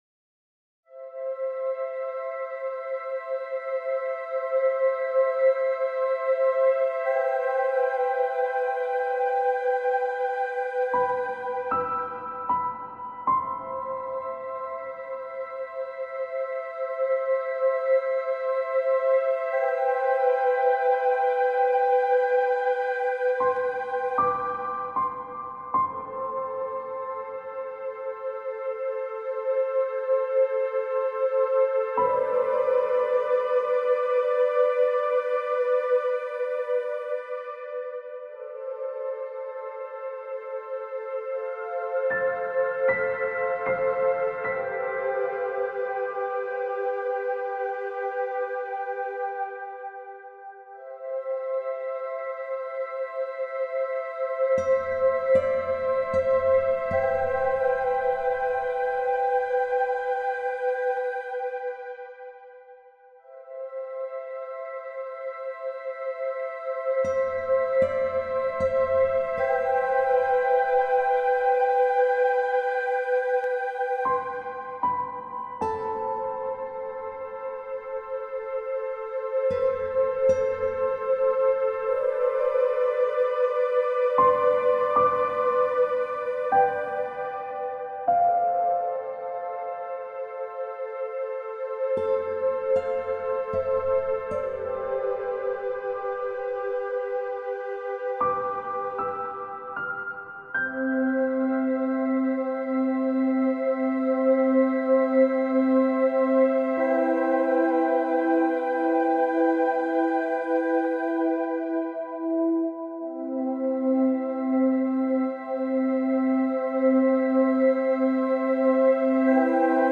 Une heure de sommeil : piano, flûte, guitare et atmosphère profonde
sons étude calmes